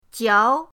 jiao2.mp3